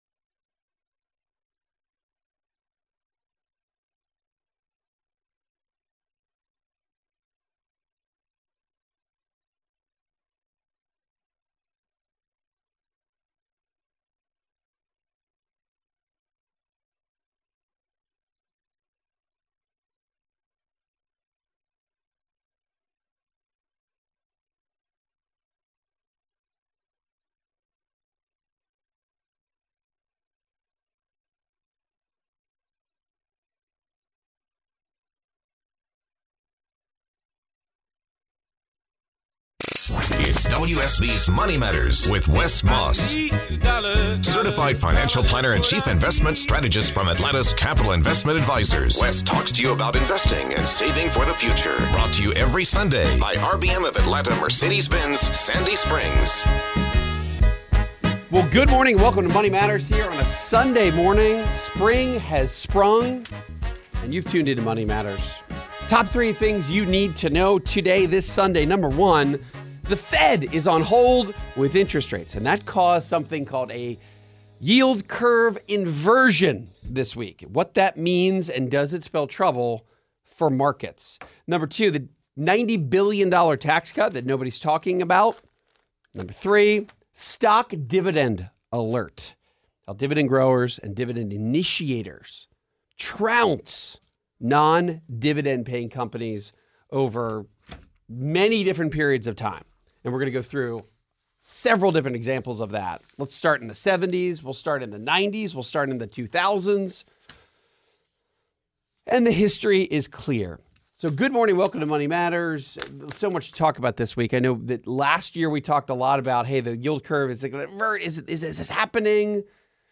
Also, caller questions include when to make a real estate purchase, will TRS for teachers stay viable, Roth conversion, and saving in a SEP IRA.